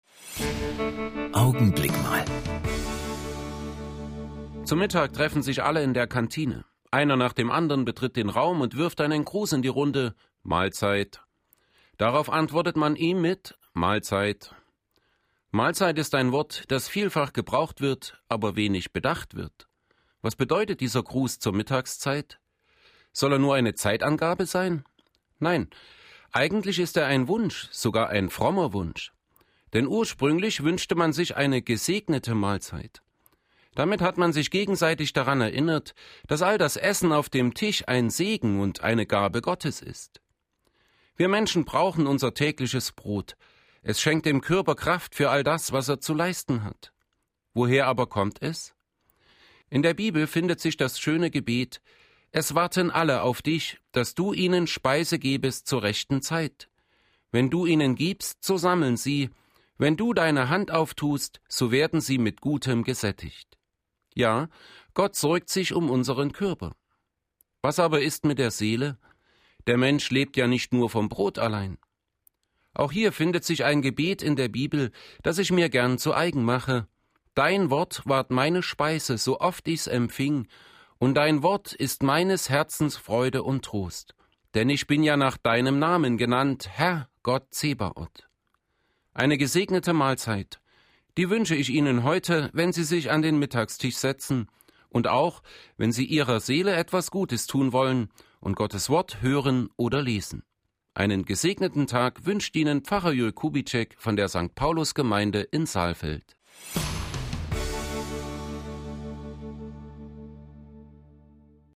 Radioandachten Gottesdienst